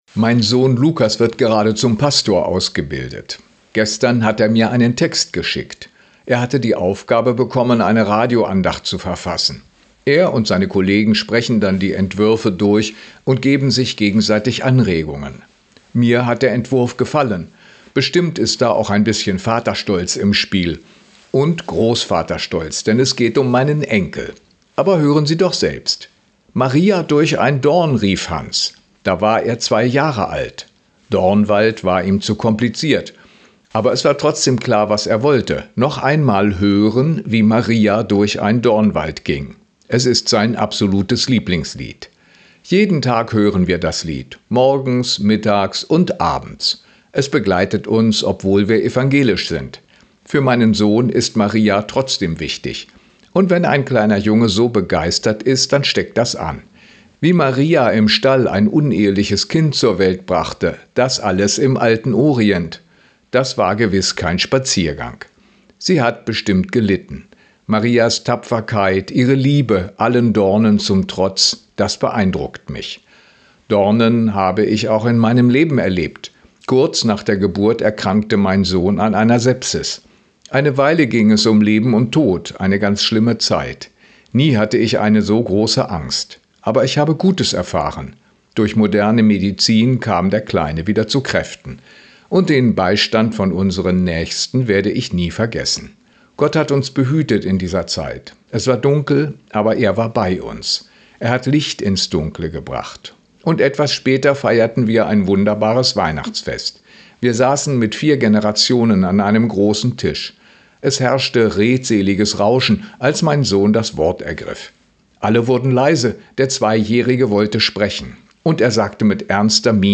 Radioandacht vom 4. November